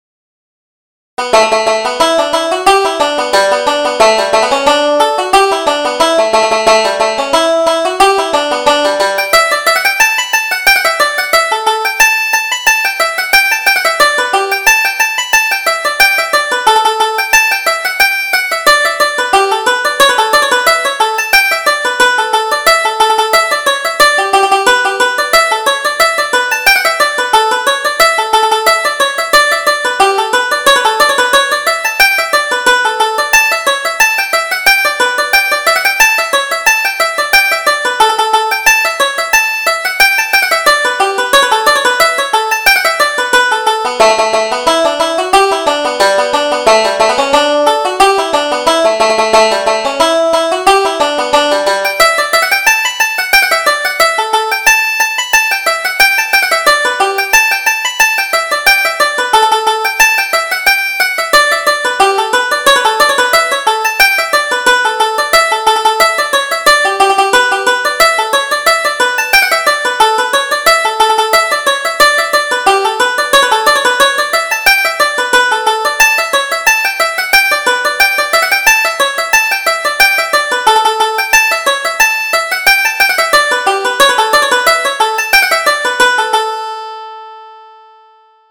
Reel: Farewell to Ireland